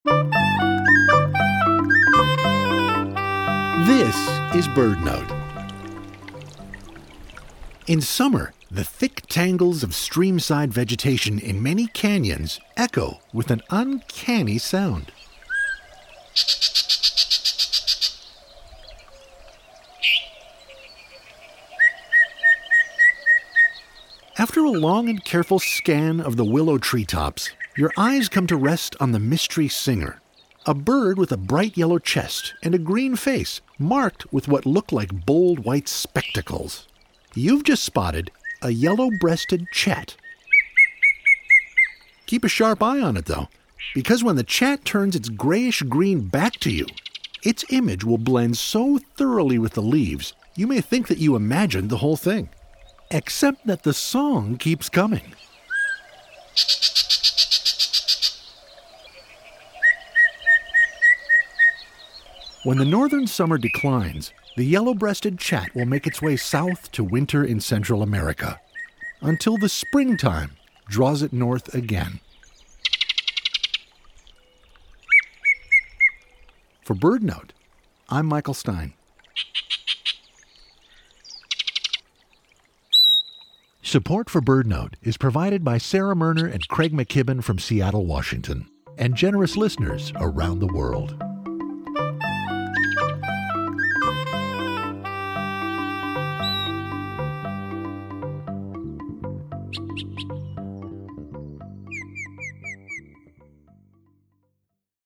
In summer, the thick tangles of streamside vegetation in many canyons echo with an uncanny sound — the Yellow-breasted Chat. You may find it in willow thickets, brushy tangles, and other dense, understory habitats, usually at low to medium elevations around streams.